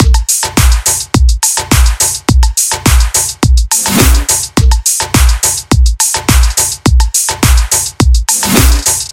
105 bpm th drum loop full
描述：这些循环是在FL Studio 12中创建的，分为4个部分：踢腿、拍子、hihat、perc和snare。我认为，这些循环可以用于热带屋和舞厅。
Tag: 105 bpm House Loops Drum Loops 1.54 MB wav Key : Unknown